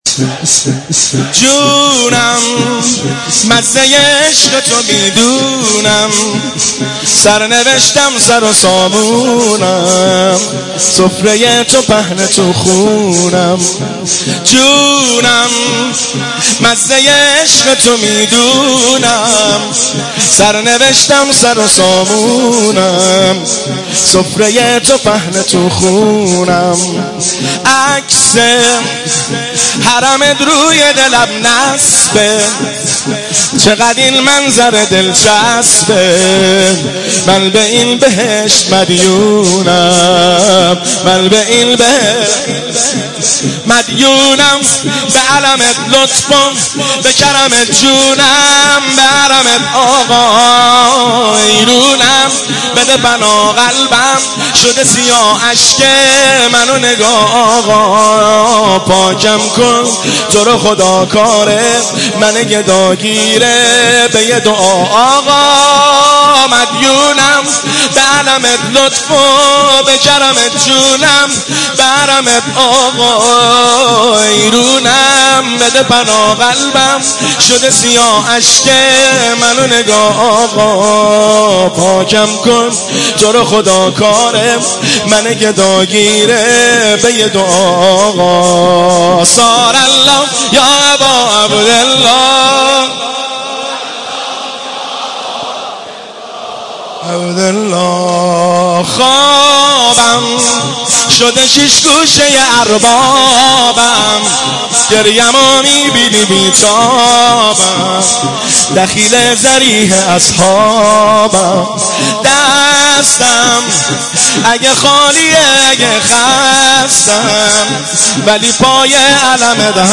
مداحی جدید
هیئت بین الحرمین طهران شب دوم محرم97
شور